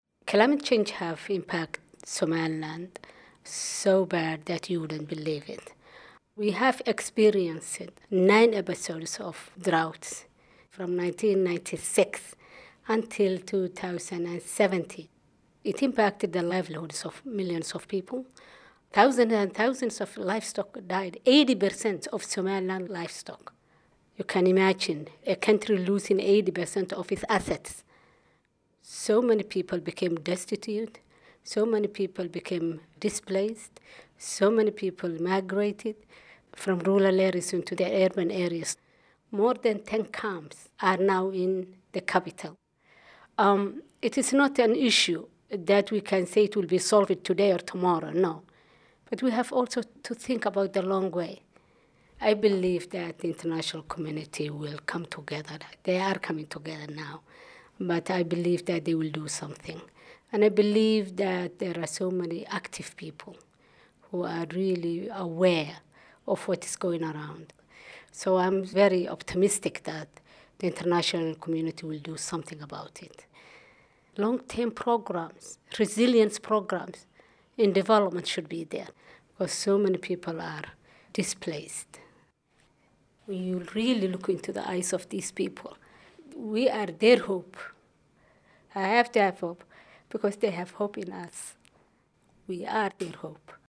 Here are some stories from the front lines of climate change that we gathered at the Global Climate Action Summit in San Francisco in mid-September (listen to each person talk by clicking the audio players below the images).
1. Shukri Haji Ismail, minister of the environment and rural development for Somaliland